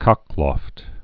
(kŏklôft, -lŏft)